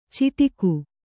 ♪ ciṭiku